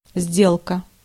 Ääntäminen
Synonyymit deal Ääntäminen : IPA : /tɹæn.ˈzæk.ʃən/ US : IPA : [tɹæn.ˈzæk.ʃən] Tuntematon aksentti: IPA : /tɹæn.ˈsæk.ʃən/ Lyhenteet ja supistumat trans tr.